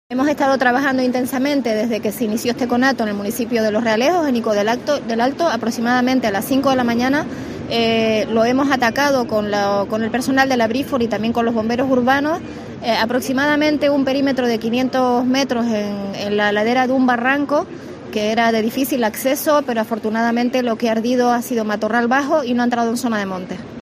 Isabel García, consejera de Medio Natural del Cabildo de Tenerife